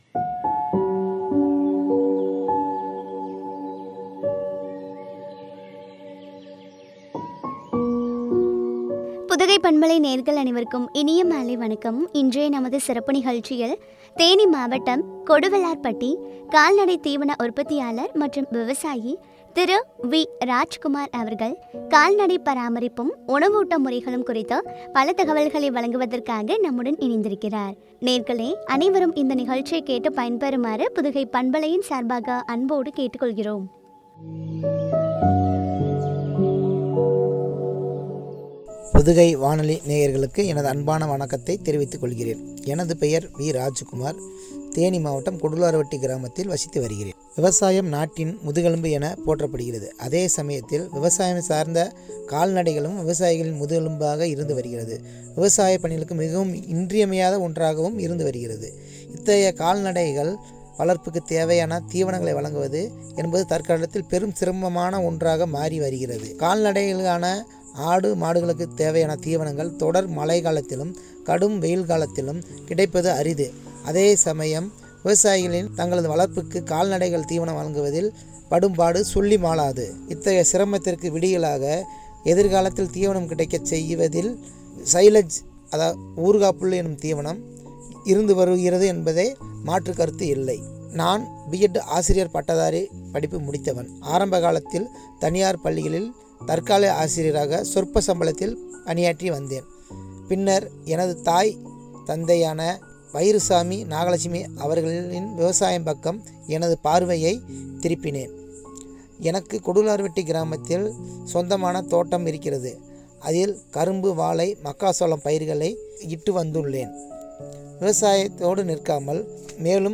உணவூட்ட முறைகளும் பற்றிய உரையாடல்.